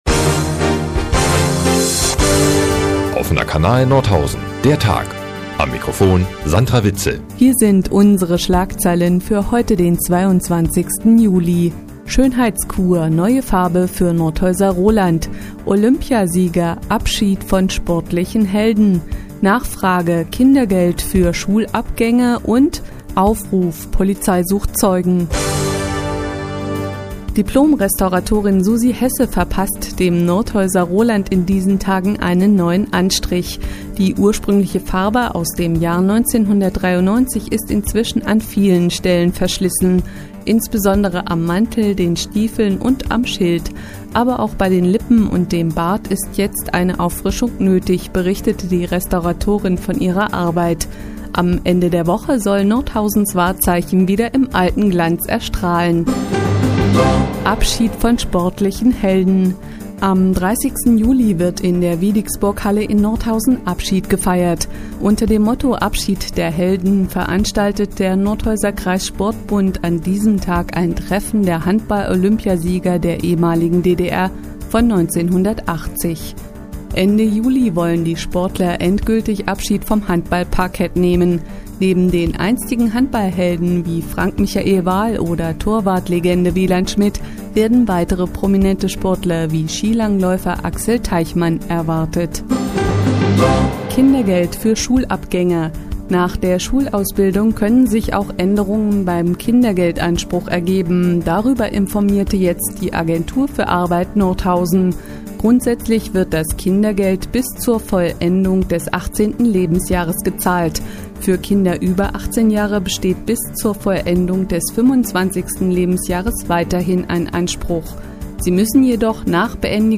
Die tägliche Nachrichtensendung des OKN ist auch in der nnz zu hören.